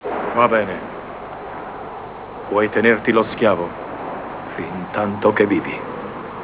Frase celebre
- doppiaggio italiano dell'epoca -